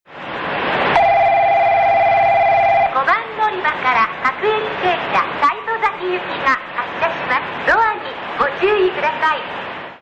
発車放送（普通・西戸崎） 九州主要 CMT